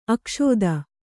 ♪ akṣōda